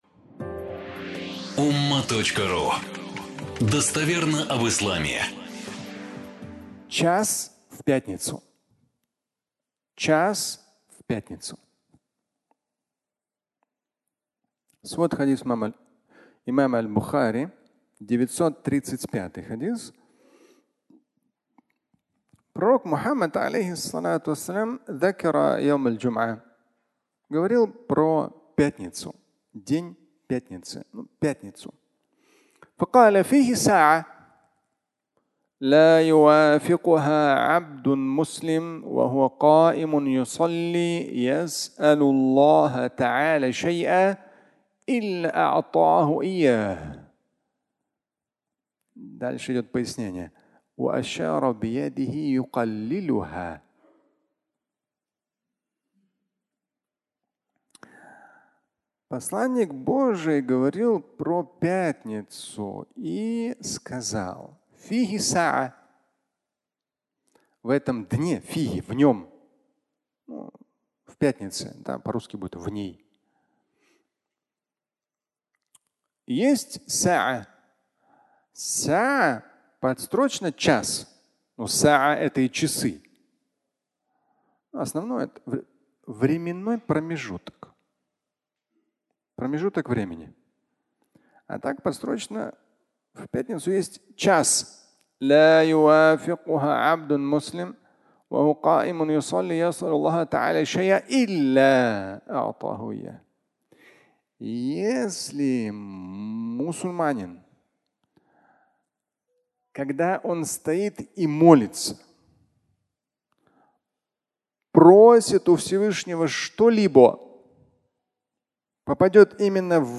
Час в пятницу (аудиолекция)